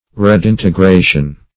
Redintegration \Re*din`te*gra"tion\ (-gr?"sh?n), n. [L.